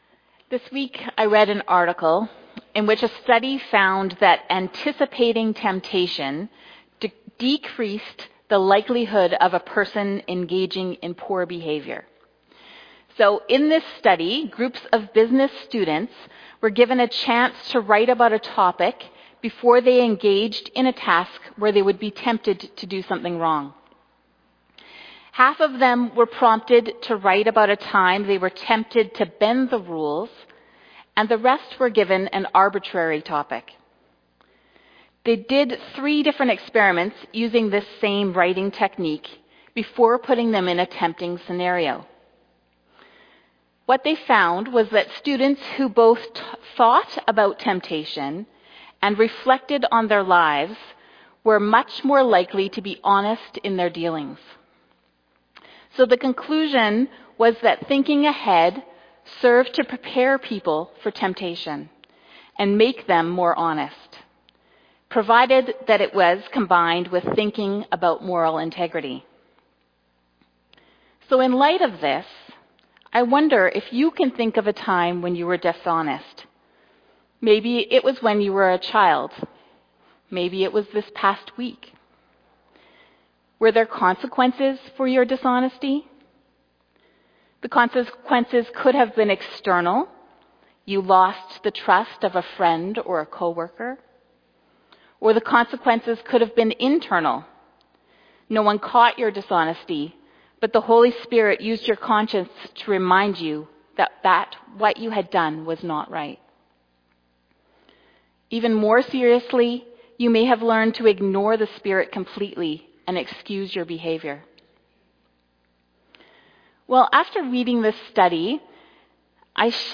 2025 Sermon August 24 2025